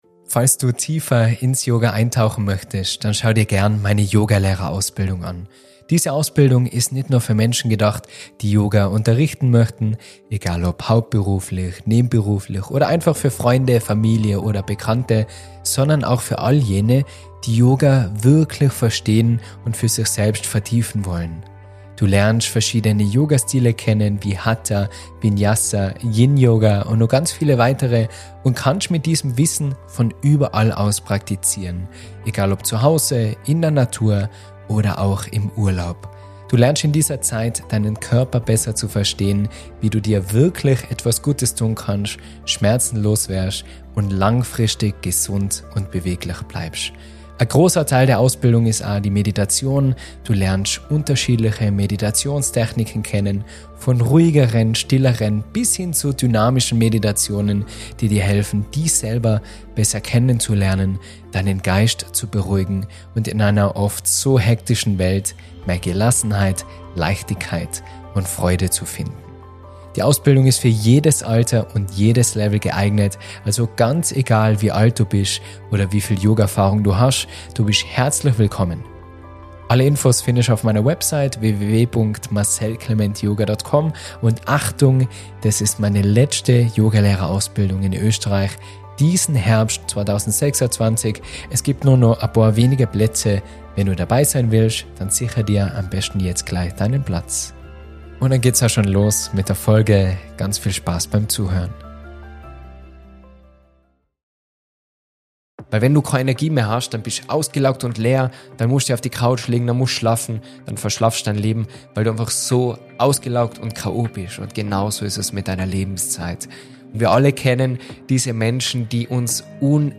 In diesem Vortrag spreche ich über das dritte Yama der Yoga-Philosophie: Asteya. Es geht dabei nicht darum, keine Wertgegenstände zu stehlen – das sollte selbstverständlich sein.